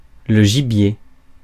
Ääntäminen
Synonyymit viande noire Ääntäminen France: IPA: [ʒi.bje] Haettu sana löytyi näillä lähdekielillä: ranska Käännös Konteksti Ääninäyte Substantiivit 1. catch kalastus, metsästys US 2. game metsästys US Suku: m .